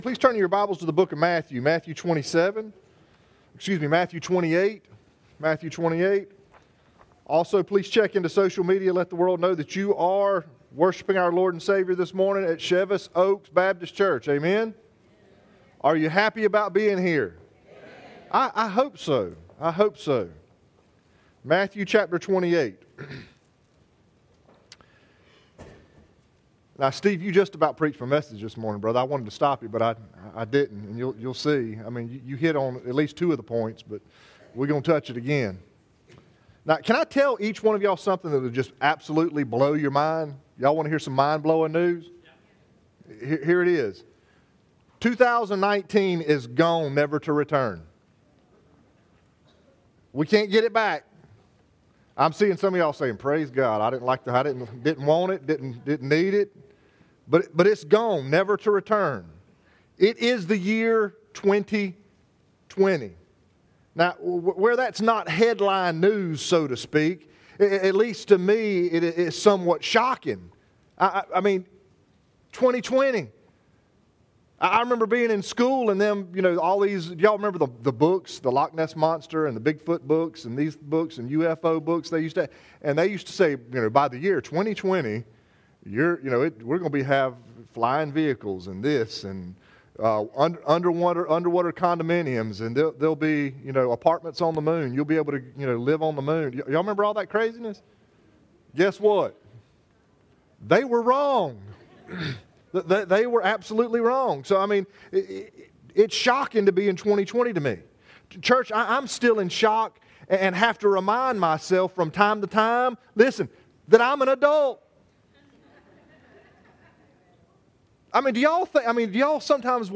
Chevis Oaks Baptist Church Sermons